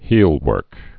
(hēlwûrk)